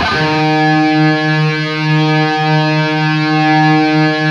LEAD D#2 LP.wav